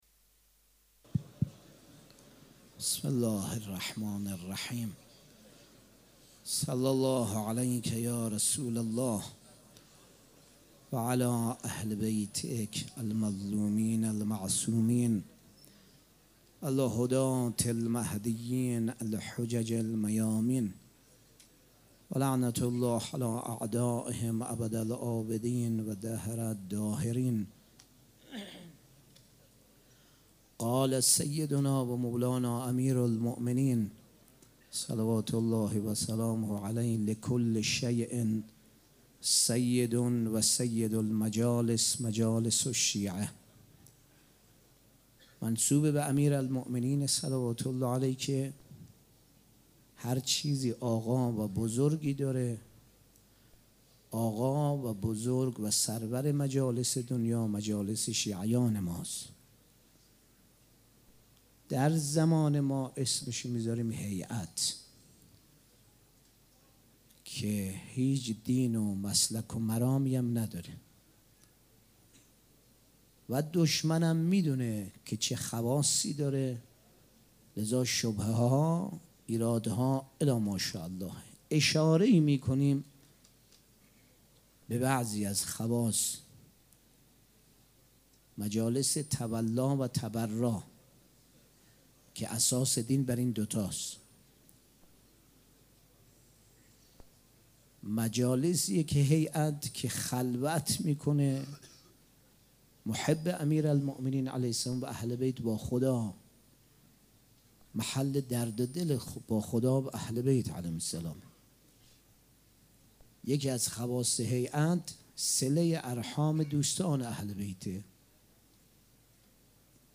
سخنرانی
شب هفتم محرم الحرام‌ شنبه ۱7 مهرماه ۱۳۹۵ هيئت ريحانة الحسين(س)
مراسم عزاداری شب هفتم